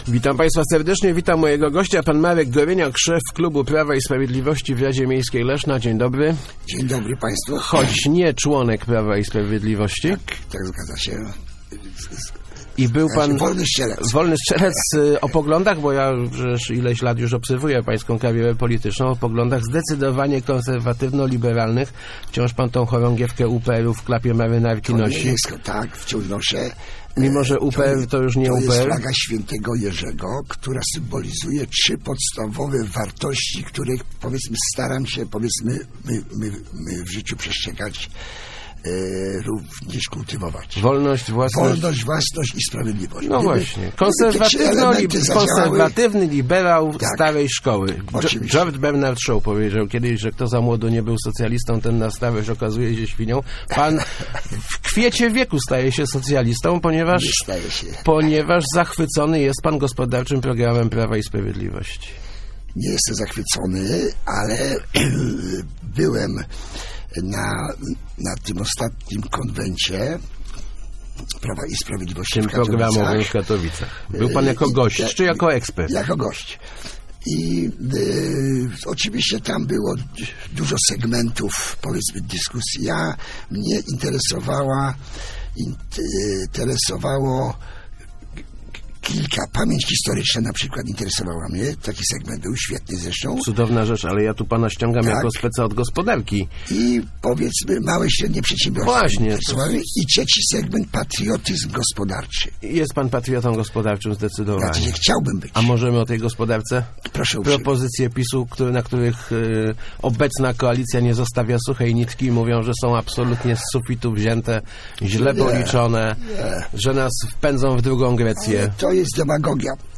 Jestem libera�em, ale podoba mi si� wiele pomys�ów PiS na gospodark� – mówi� w Rozmowach Elki Marek Goryniak, szef klubu PiS w Radzie Miejskiej Leszna.